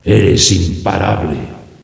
flak_m/sounds/announcer/est/unstoppable.ogg at efc08c3d1633b478afbfe5c214bbab017949b51b